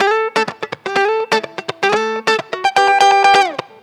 Electric Guitar 09.wav